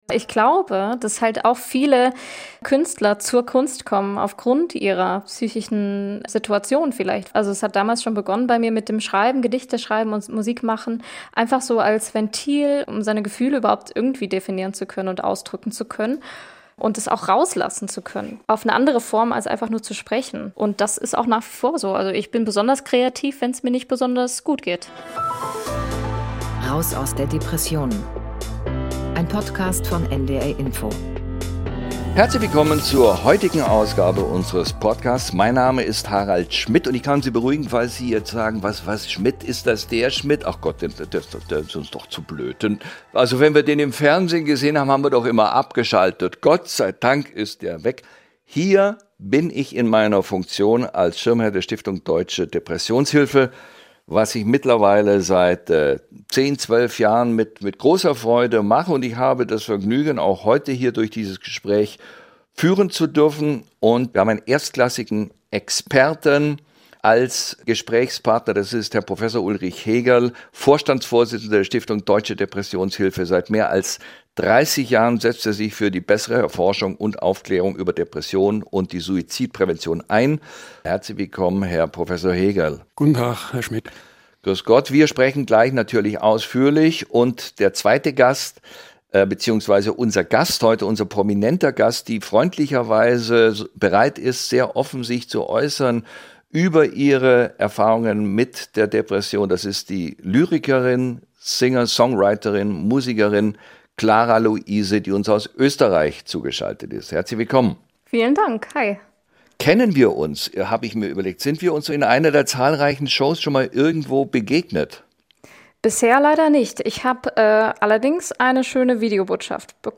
Experteninterview